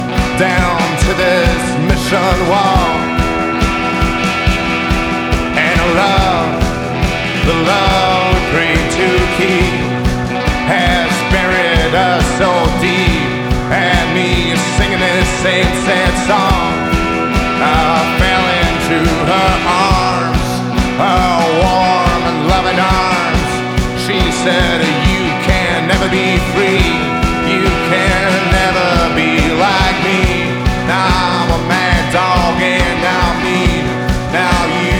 Rock Psychedelic Adult Alternative Alternative Indie Rock
Жанр: Рок / Альтернатива